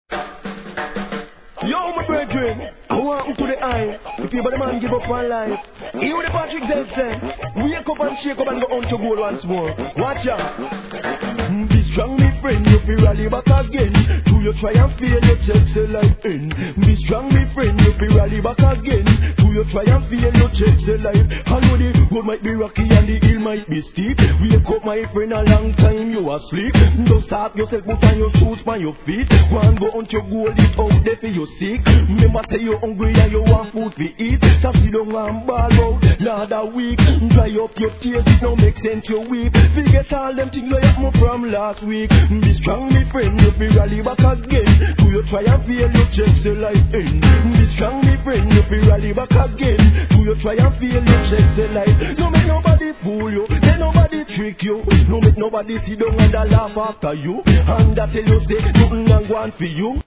REGGAE
RHYTHMの抜きもはまるDeeJay物